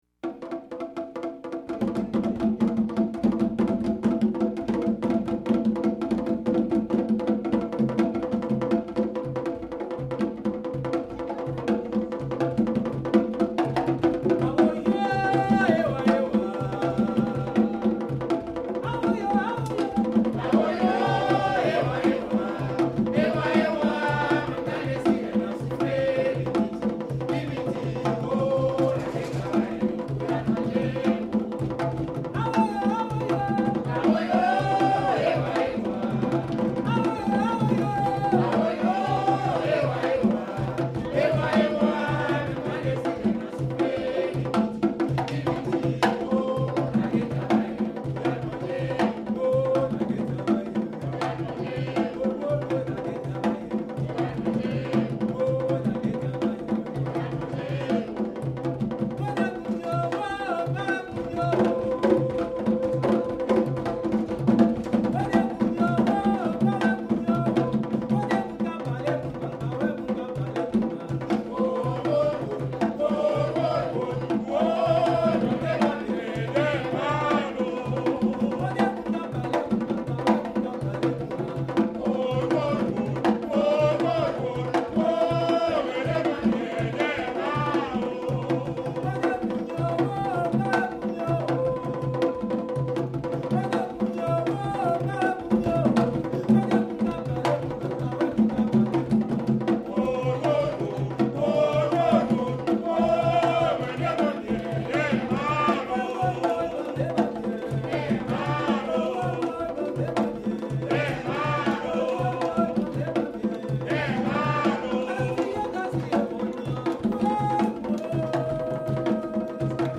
Rara Songs
This is a medley of Rara songs. Rara is a traditional celebration held during the Lenten season in Haiti that includes percussion, costuming, dance, and music played by an array of percussion and wind instruments.
lead vocal
Recorded at a concert of the Historical Museum.